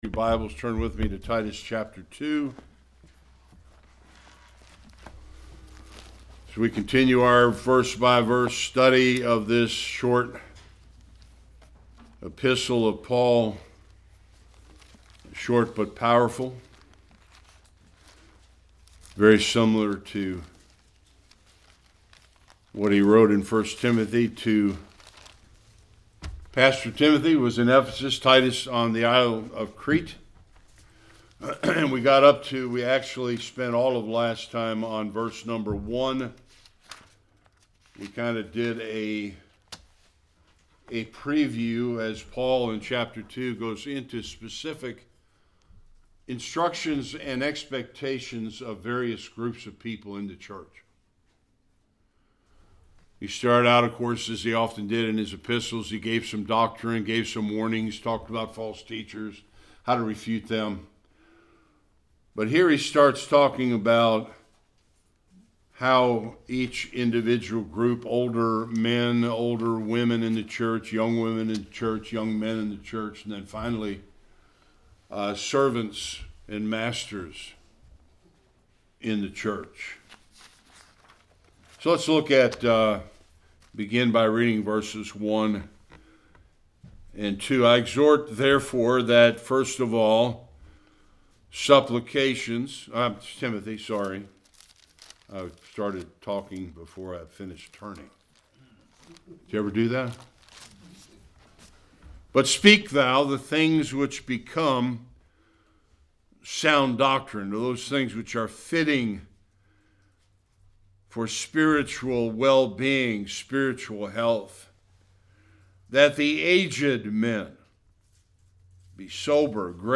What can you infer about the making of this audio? Titus 2:2 Service Type: Sunday Worship What should be true of the older men in the church?